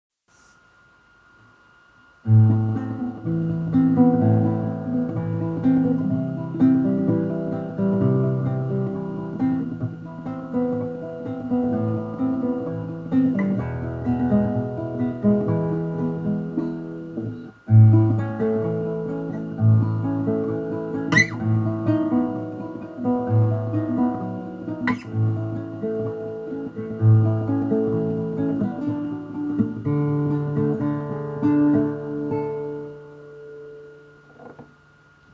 Anotace: Písničkobásničkokravinka č.3. Zpěv dodám, až vymyslím melodii, tak zatím jenom nástřel kytara a text:-)
Pěkná báseň, s přesahem, a sympatická kytara :-)